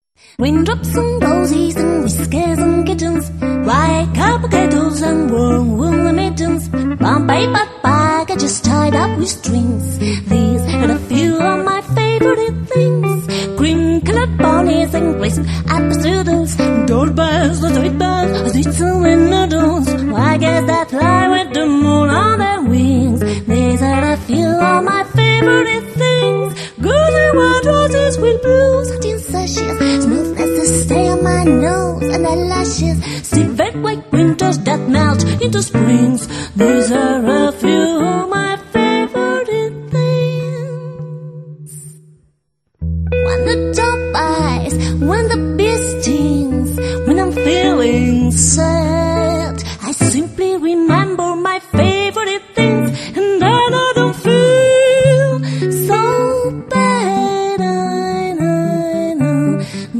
chitarra
voce